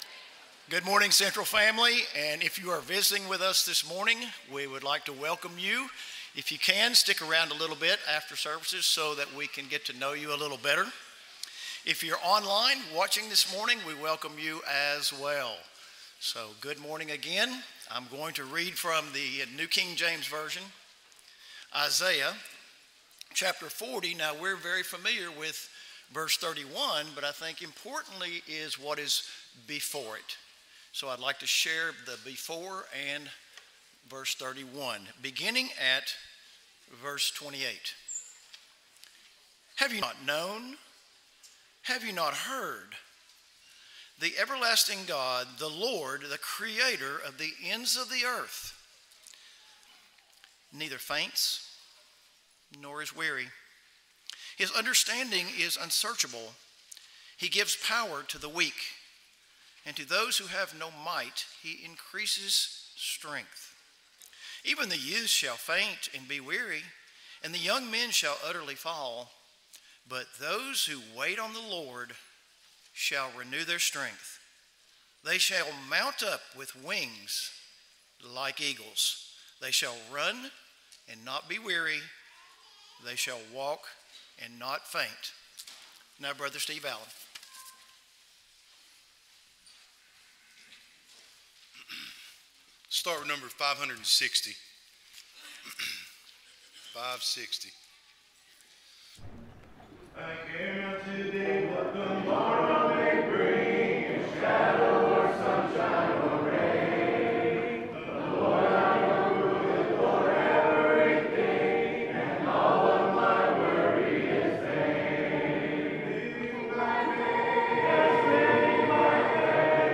Psalm 118:8, English Standard Version Series: Sunday AM Service